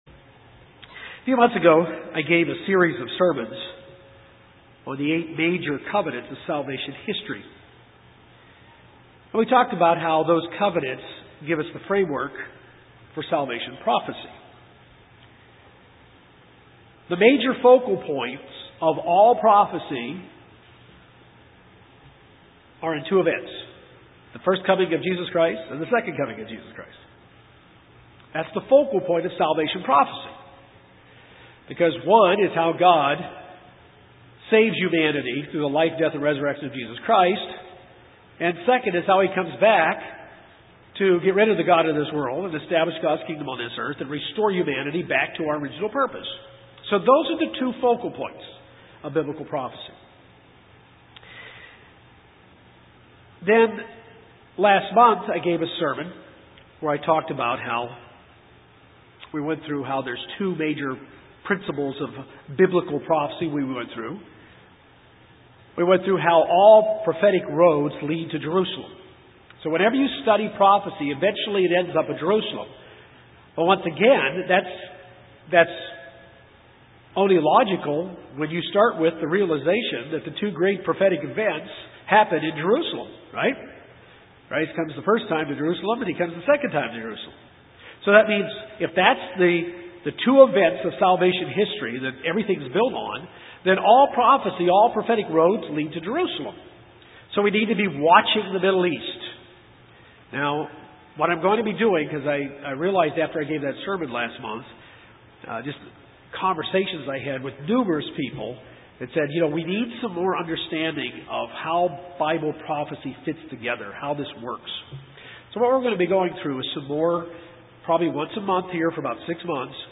The first coming of Jesus Christ and the second coming of Jesus Christ are the two major focal points of Biblical Prophecy. All prophetic roads lead to Jerusalem. This sermon will begin with some very basic core guidelines for understanding biblical prophecy.